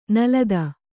♪ nalada